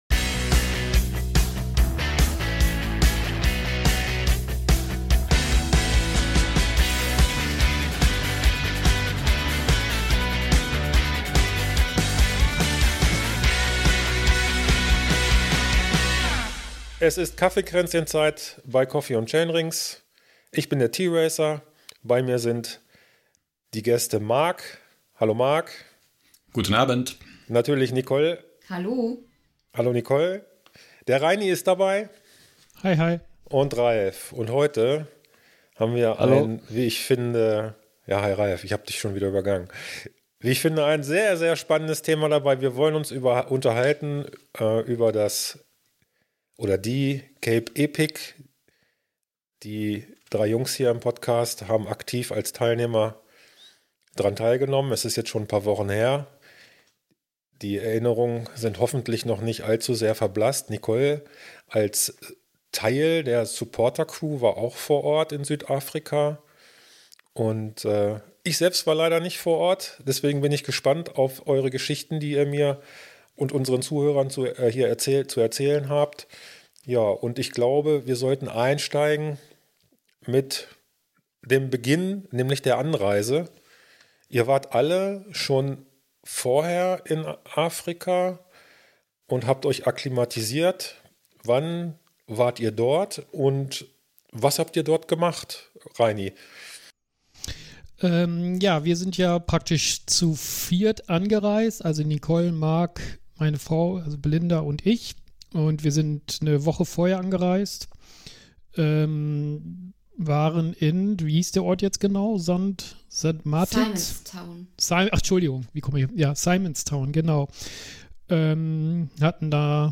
Hört heute welche spannende, kuriose und aufregende Geschichten unsere Cape Epic-Teilnehmer zu erzählen haben.